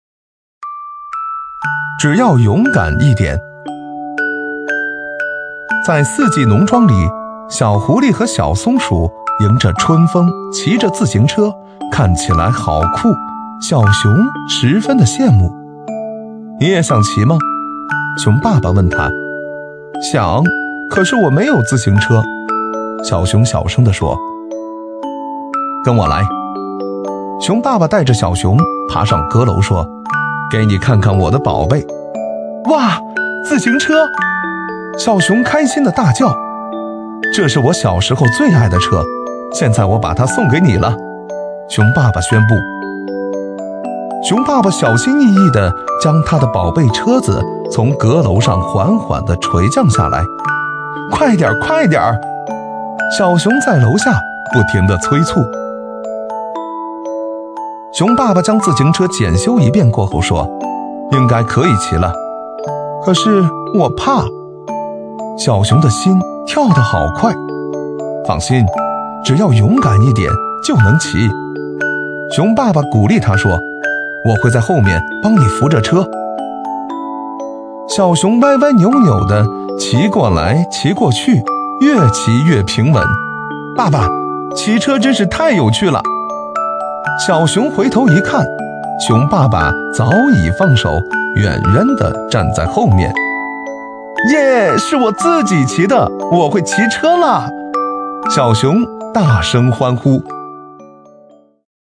【男50号绘本】小熊讲故事
【男50号绘本】小熊讲故事.mp3